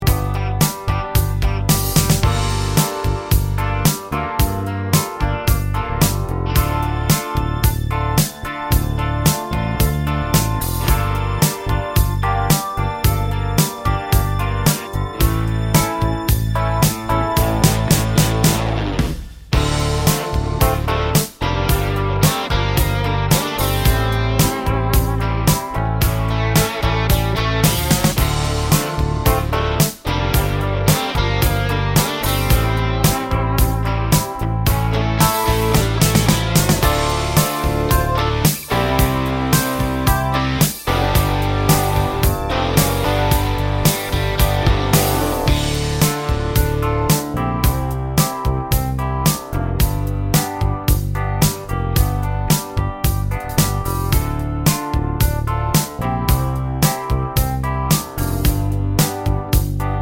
no Backing Vocals Country (Male) 4:18 Buy £1.50